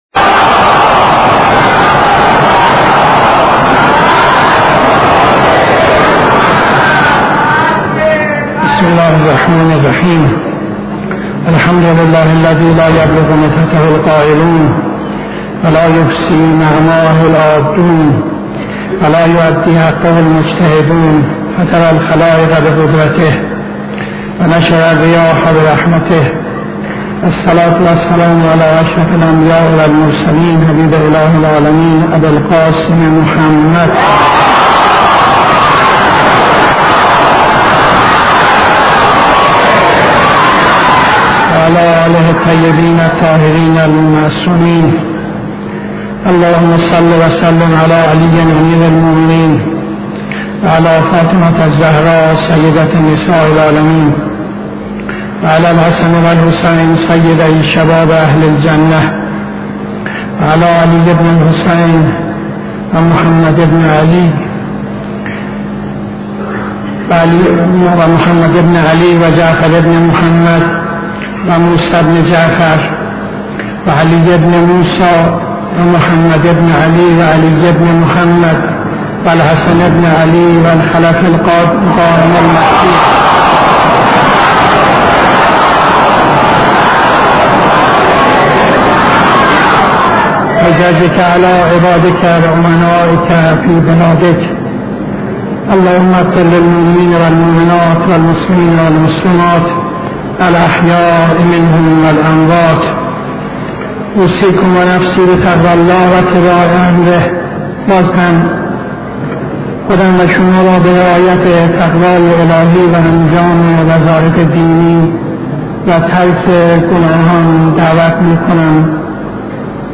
خطبه دوم نماز جمعه 24-09-74